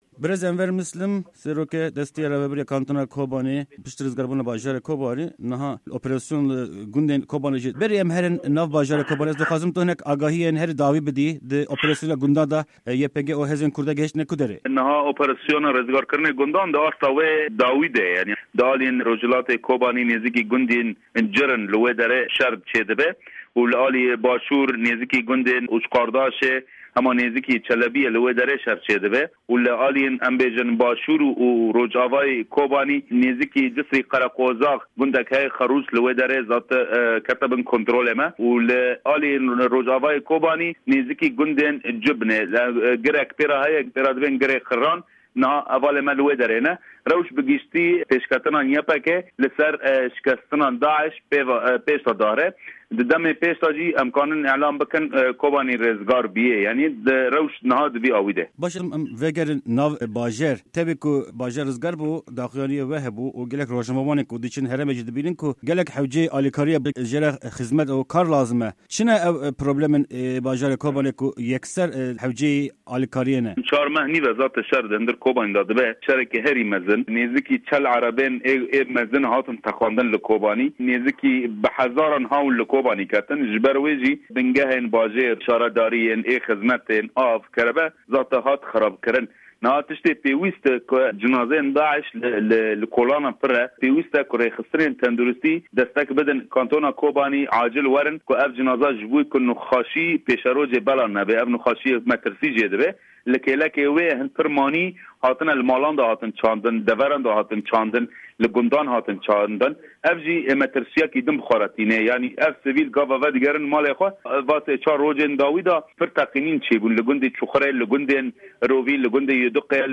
Di hevpeyîna Dengê Amerîka de Serokwezîrê Kantona Kobanê, Enwer Mislim li ser hebûna mayin û bombeyan agahîyên girîng dide.